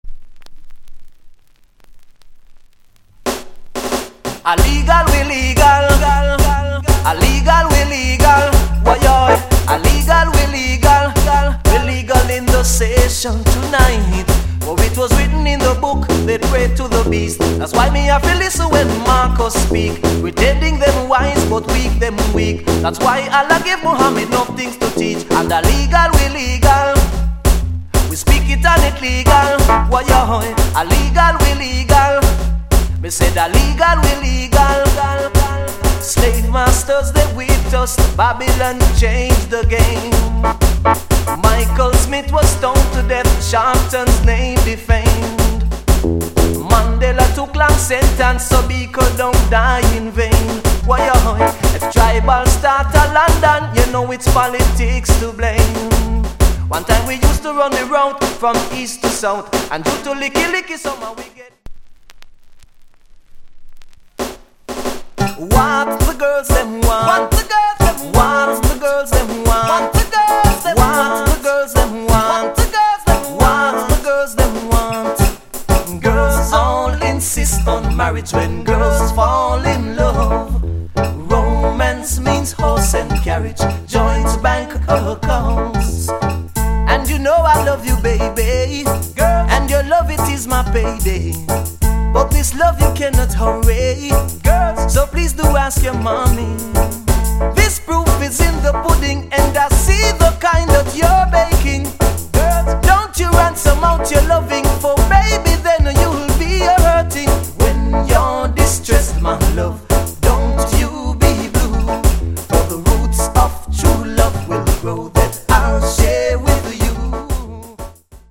* New York Singer Good Vocal!!